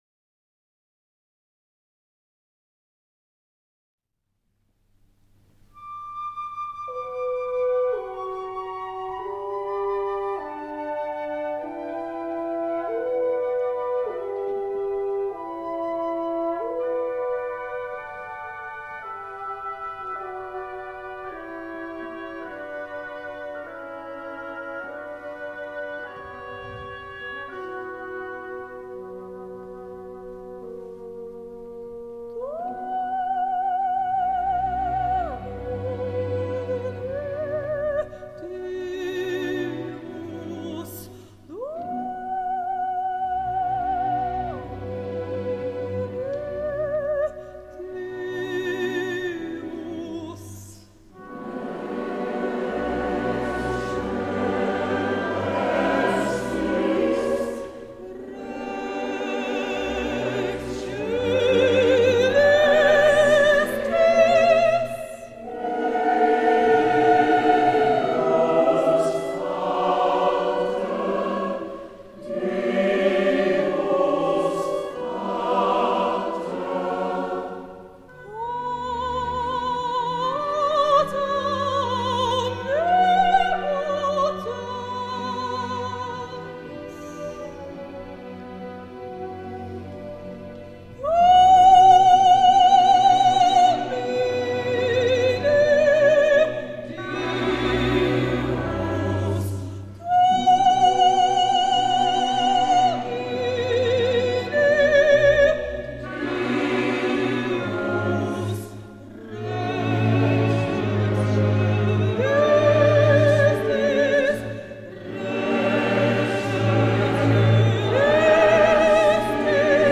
Aufnahme aus dem Konzerthaus Neue Welt, Zwickau Dortmunder Oratoriumchor e.V.
Chorvereinigung Sachsenring Zwickau e.V.
Philharmonischer Orchester Zwickau